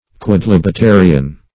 quodlibetarian.mp3